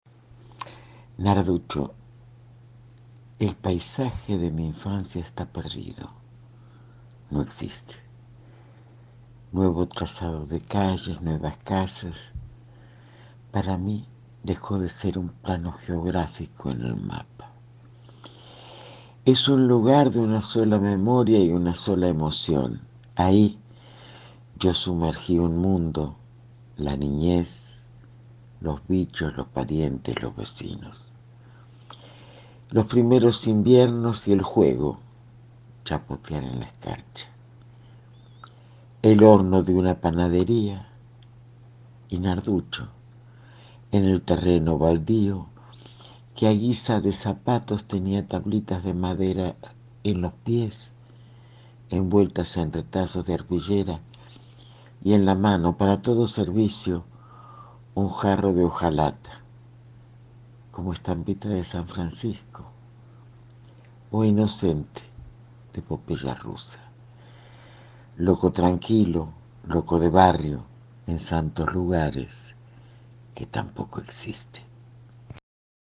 Leído por la autora